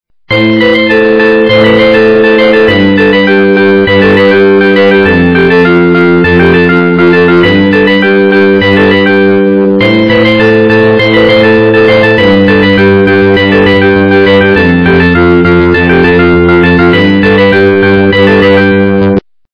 качество понижено и присутствуют гудки
полифоническую мелодию